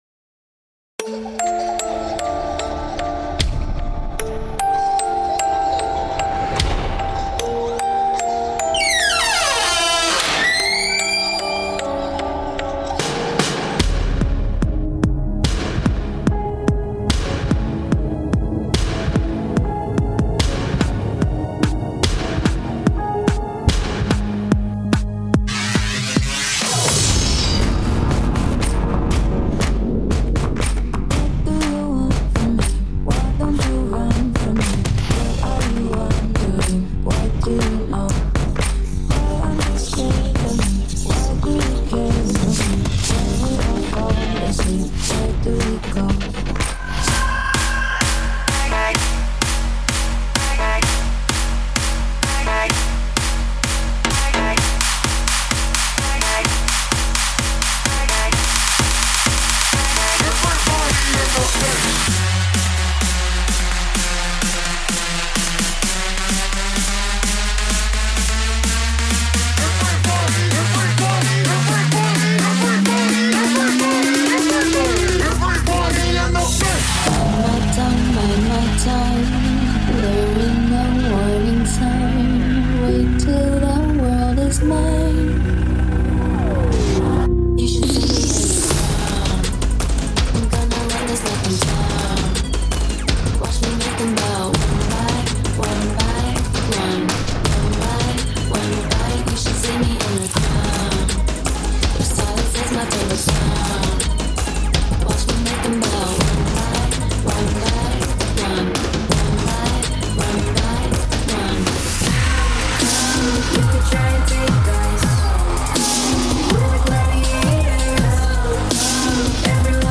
Hip hop or Kick